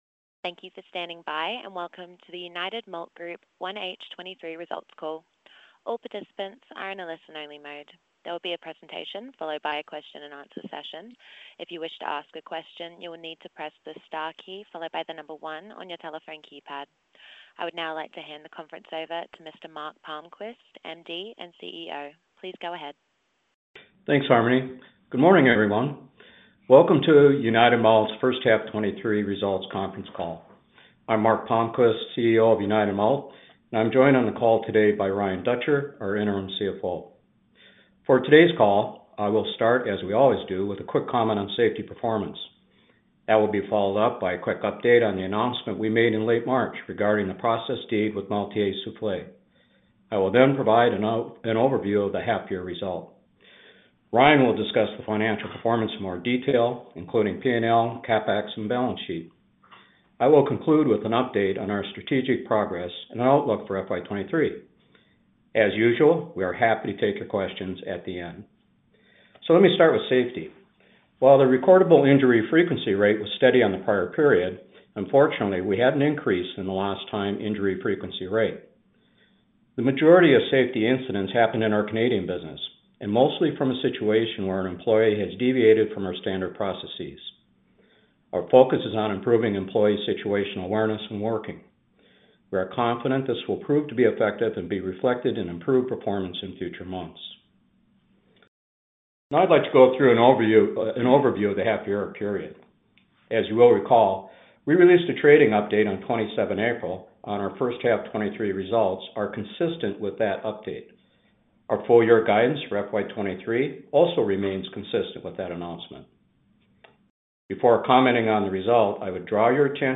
Scheme Meeting - 12 October 2023